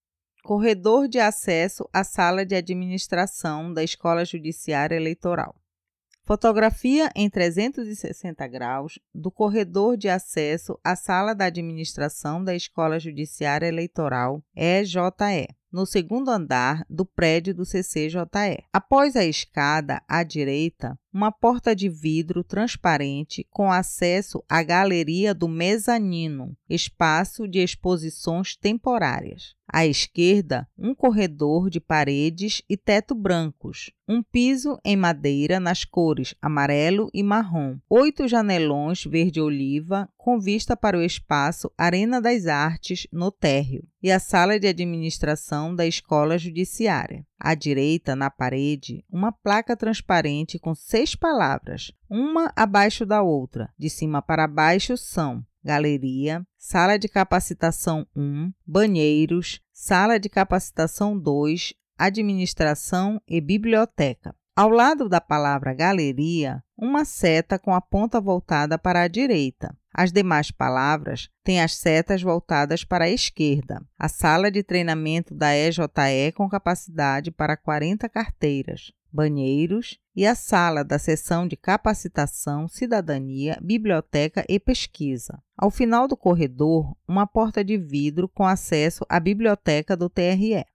Corredor de Acesso à Sala de Administração da EJE audiodescrição